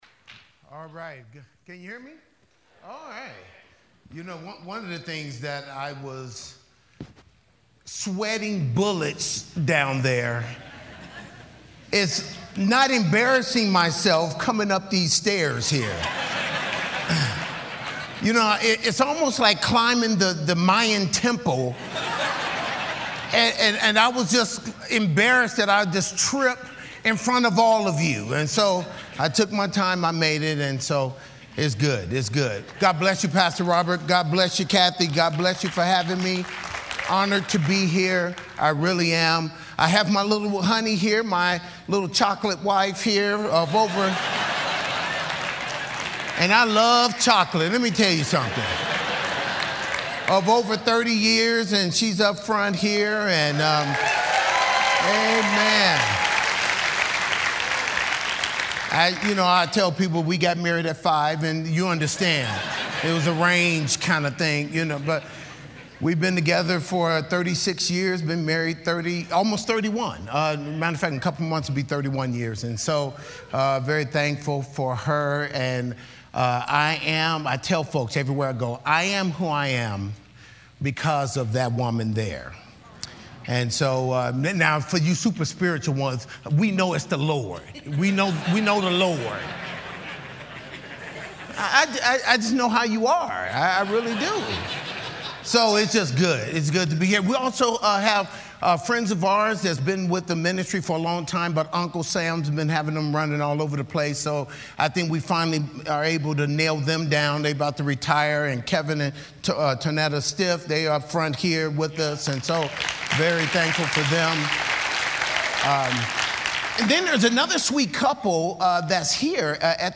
Southwest Pastors and Leaders Conference 2016